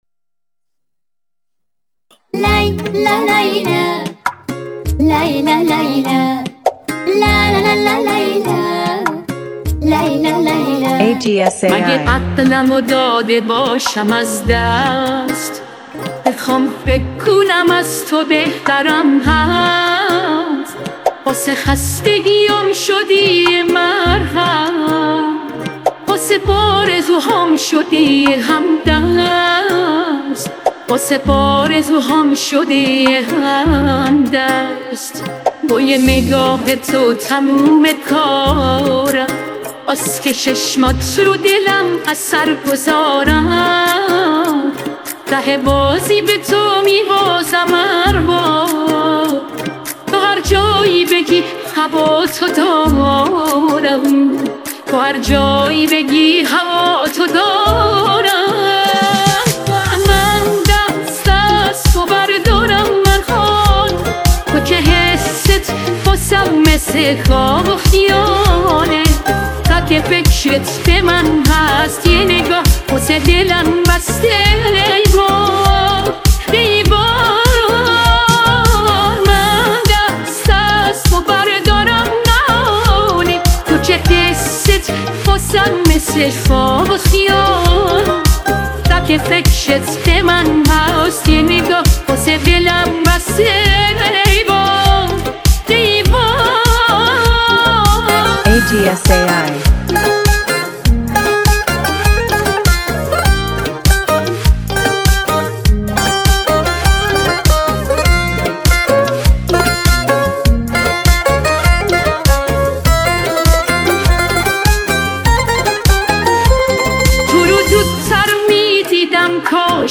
اهنگ سبک کانتری